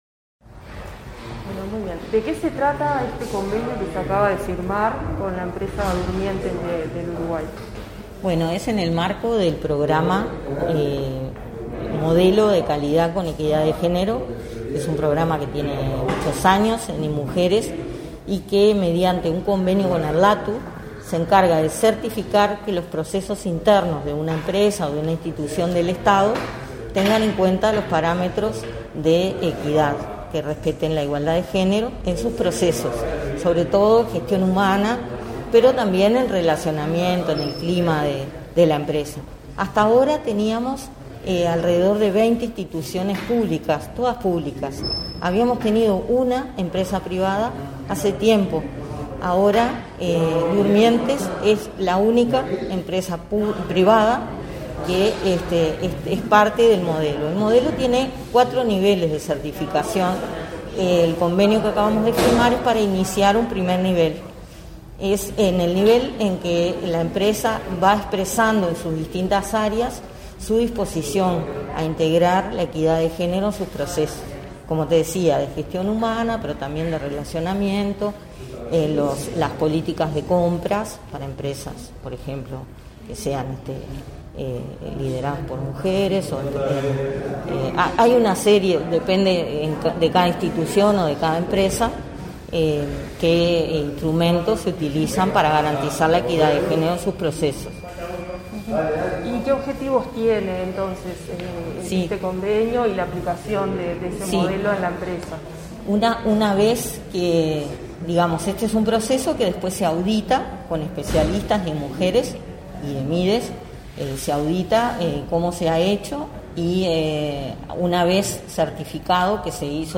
Declaraciones de la directora del Instituto Nacional de las Mujeres, Mónica Bottero
Declaraciones de la directora del Instituto Nacional de las Mujeres, Mónica Bottero 23/11/2021 Compartir Facebook X Copiar enlace WhatsApp LinkedIn El Ministerio de Desarrollo Social firmó el primer convenio con una empresa privada para promover la equidad de género, este 23 de noviembre. Tras el evento, la directora del Instituto Nacional de las Mujeres, Mónica Bottero, efectuó declaraciones a Comunicación Presidencial.